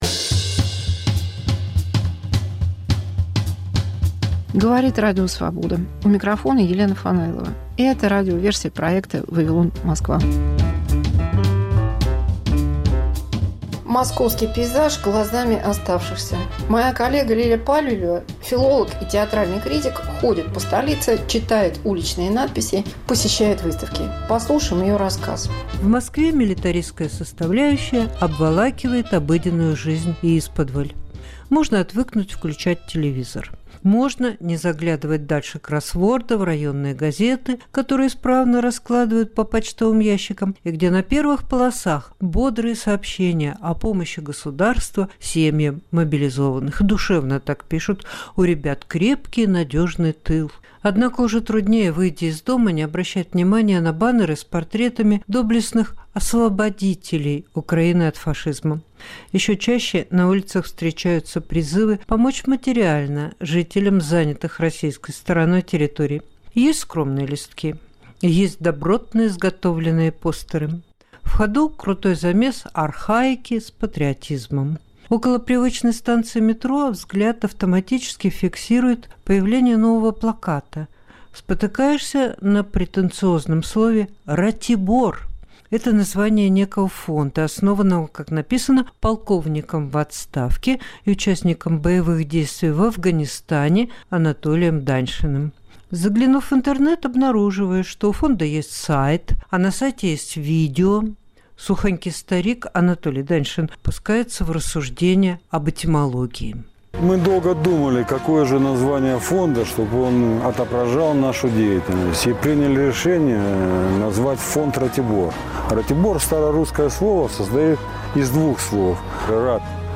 Мегаполис Москва как Радио Вавилон: современный звук, неожиданные сюжеты, разные голоса.